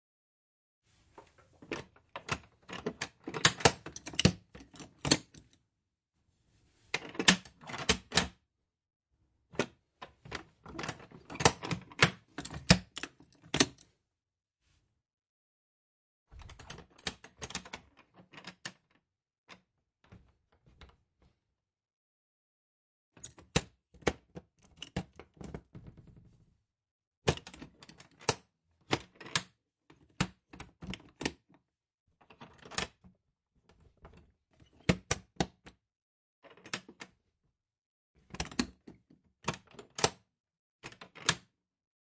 乌干达 " 木制门，沉重的仿古城堡，打开关闭，响亮的手柄和锁，解锁，锁闩，点击 +BG宿舍激活
描述：门木重型古董城堡打开关闭和拨浪鼓手柄和锁解锁deadbolt闩锁点击+ bg宿舍活动onmic.wav
标签： 点击 打开锁 城堡 关闭 开锁 打开 门栓 拨浪鼓 拉手 古色古香
声道立体声